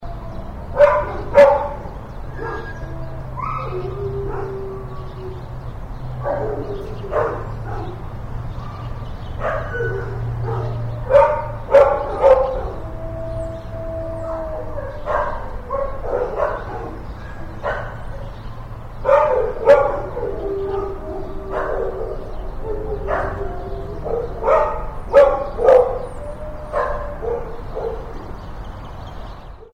جلوه های صوتی
دانلود صدای سگ در باغ و طبیعت از ساعد نیوز با لینک مستقیم و کیفیت بالا